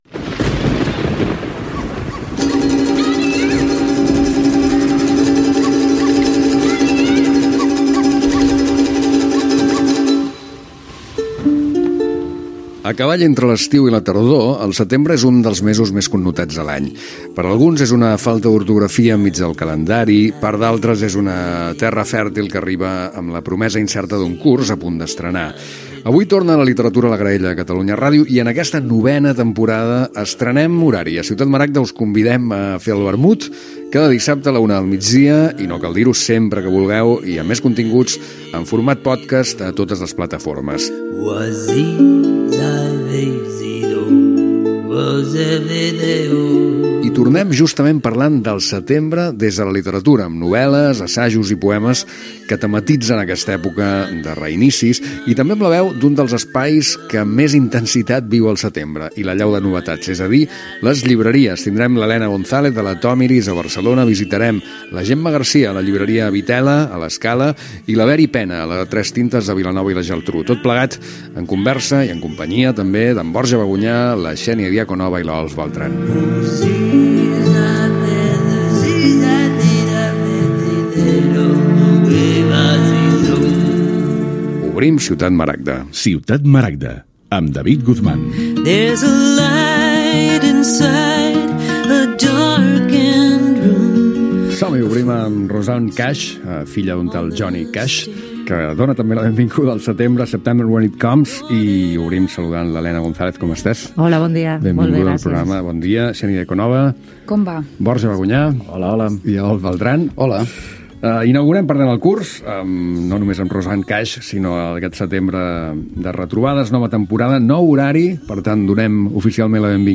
Inici de la novena temporada del programa. Sumari, indicatiu, salutació als quatre col·laboradorsi avís del canvi d'horari .
FM